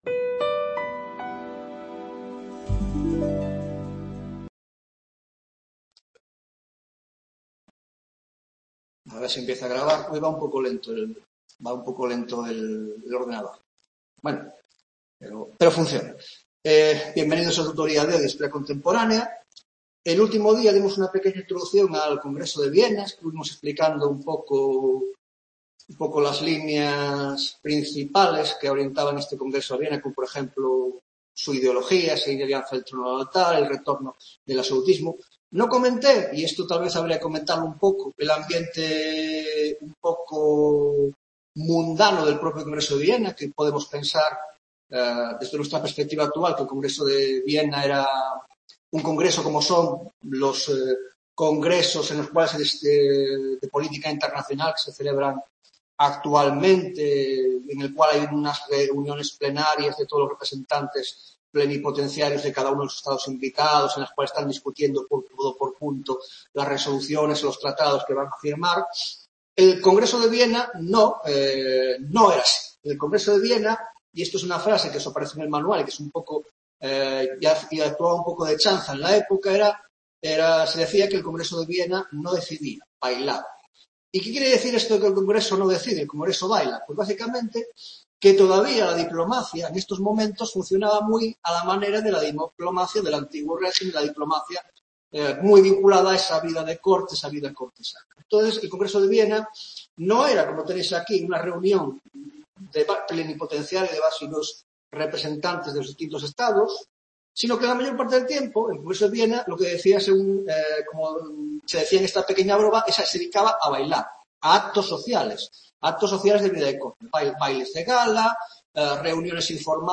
4ª Tutoría de Historia Contemporánea